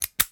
pocket-watch-close.wav